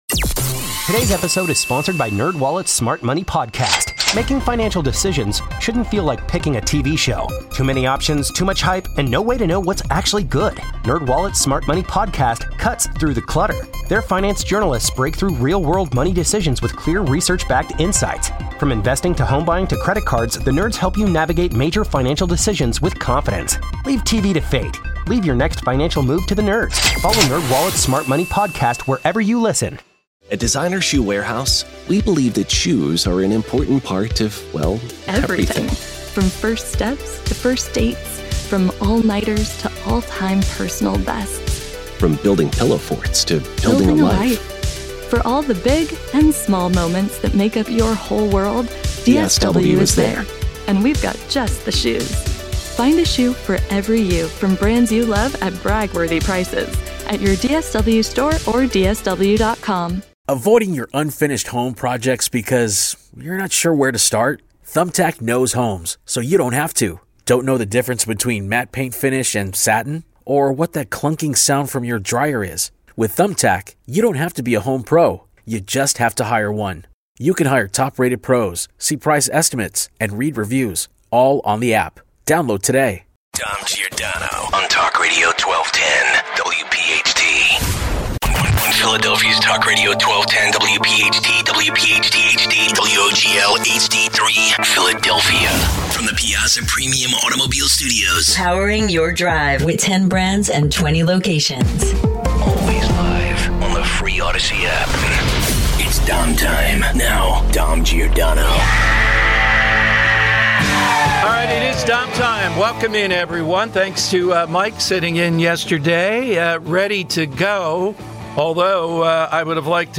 Your calls. 1230 - Will Democrats walk out on Trump’s speech tonight over trans issues?